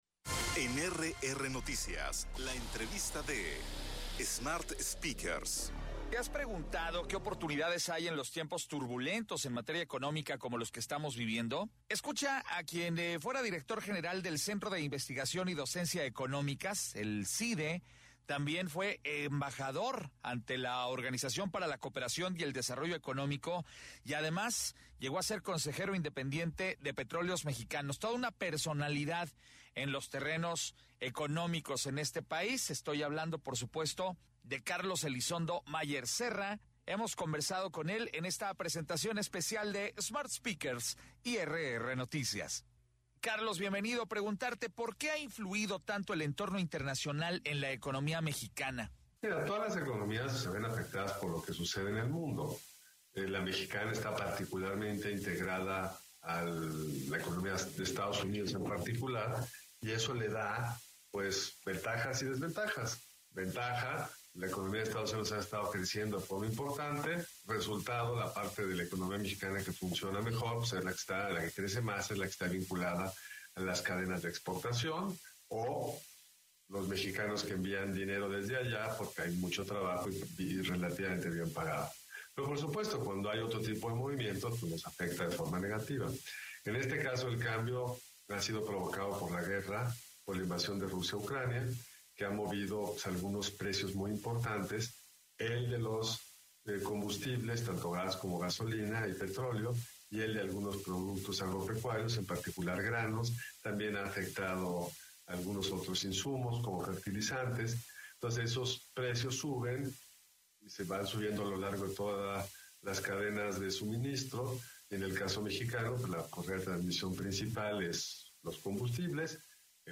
EntrevistasPodcast
Escuche la entrevista que tuvimos este día con Carlos Elizondo, exembajador ante la OCDE y consejero Independiente de Pemex hasta 2019